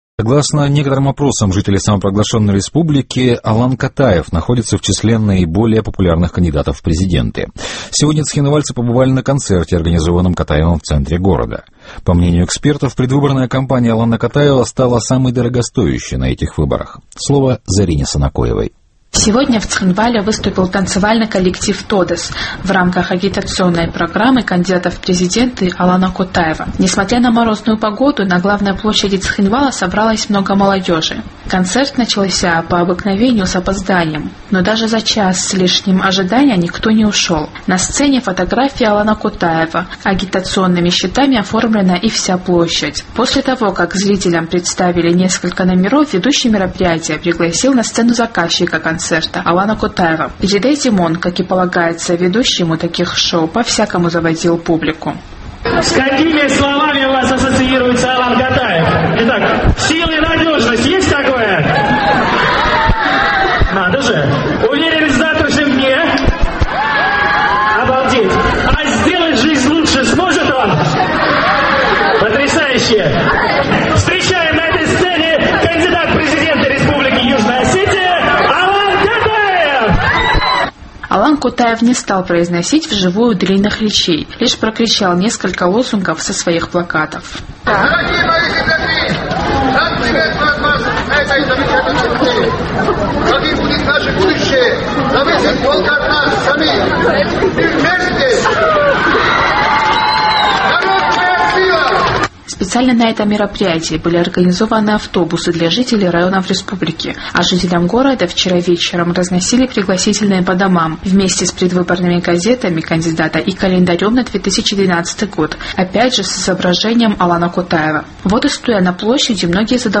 Несмотря на морозную погоду, на главной площади Цхинвала собралось много молодежи.
Кандидат в президенты не стал произносить длинных речей, лишь прокричал несколько лозунгов со своих плакатов.